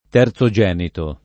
vai all'elenco alfabetico delle voci ingrandisci il carattere 100% rimpicciolisci il carattere stampa invia tramite posta elettronica codividi su Facebook terzogenito [ t H r Z o J$ nito ] agg. e s. m.; pl. m. terzogeniti